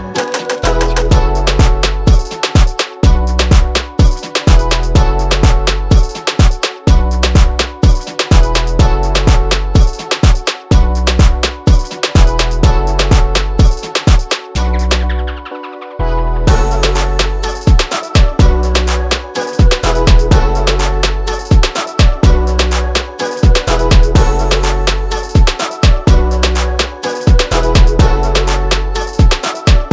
• Latino